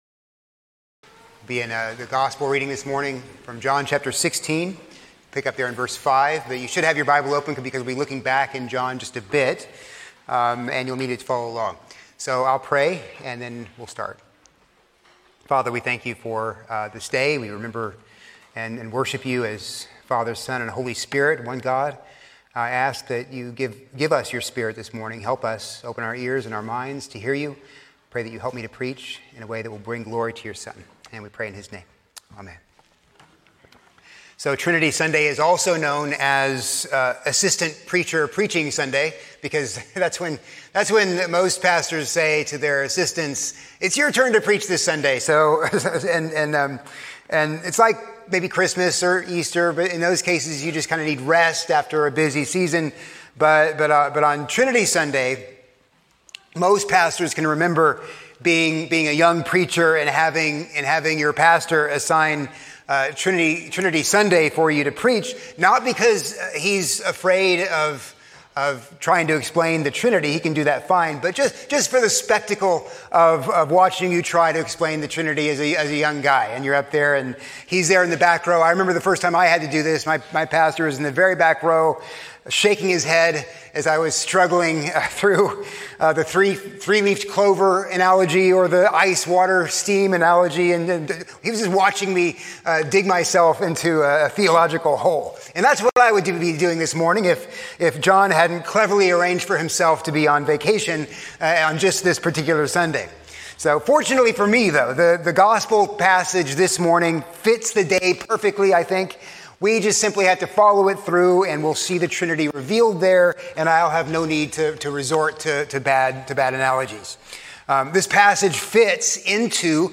A sermon on John 16:5-15